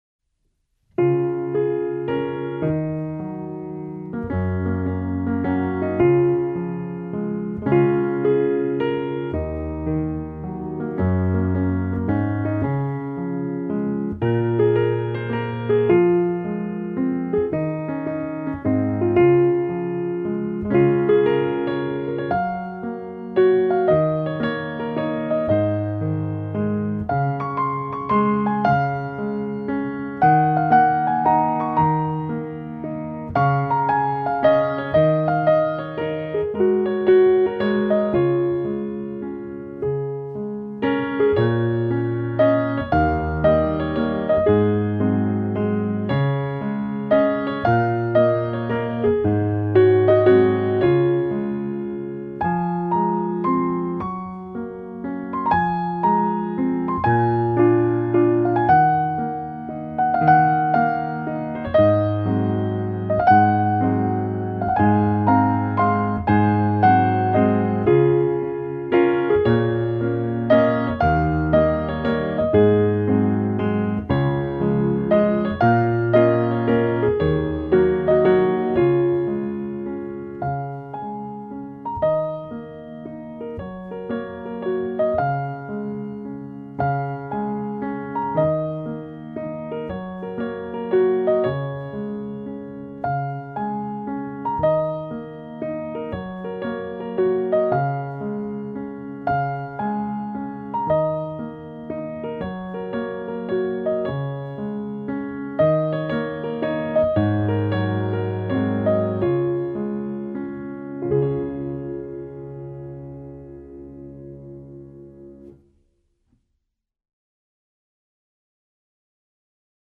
鋼琴
部分曲子加入弦樂、吉他、手風琴等樂器，呈現更豐富的音樂氛圍。
用最溫柔、平和的曲調表現出來。